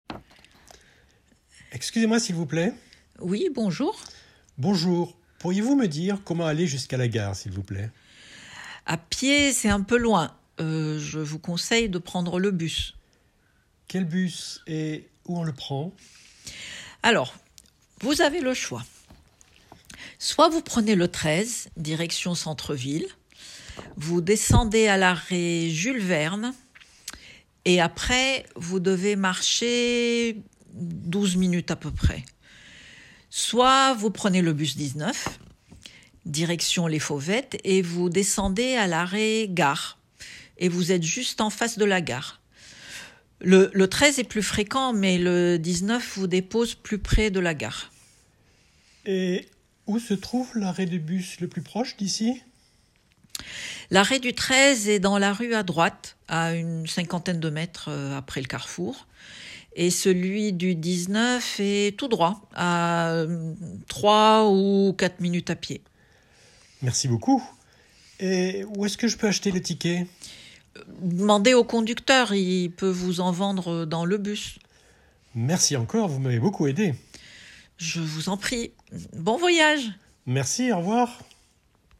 Enregistrement : demander son chemin, comprendre un itinéraire en transports en commun Catégorie : semi-authentique Type : enregistrement audio Tags : Transports ‣ informations ‣ itinéraire ‣ Télécharger la ressource.